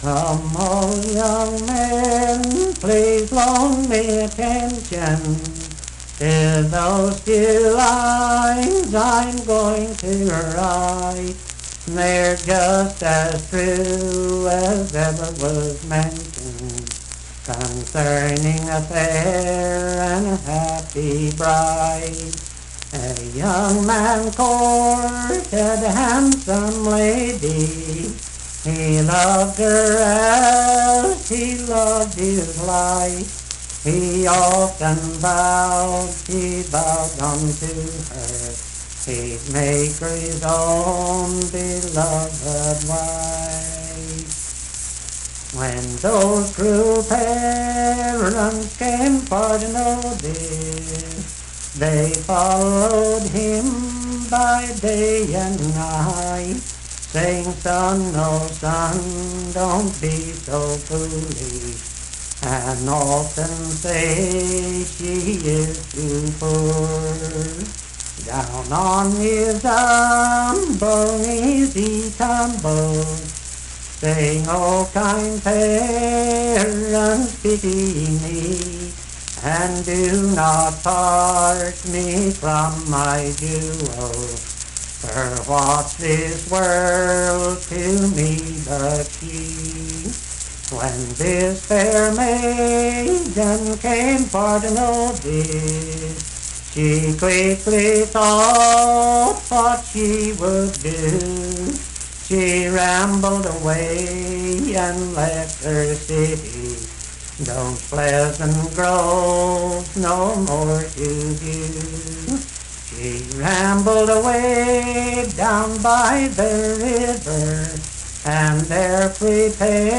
Unaccompanied vocal music
Verse-refrain 9(4).
Performed in Dryfork, Randolph County, WV.
Voice (sung)